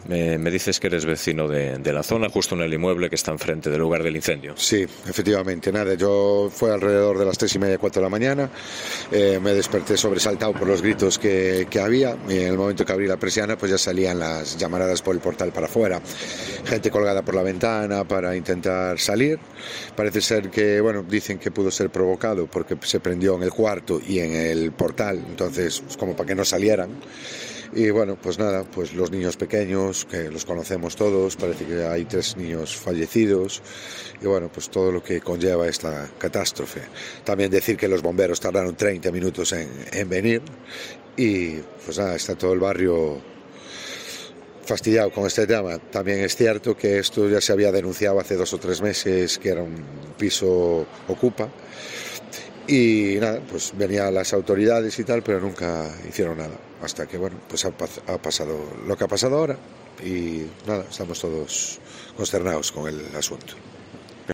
Un vecino del edificio incendiado en Vigo esta madrugada: Salían las llamaradas por el portal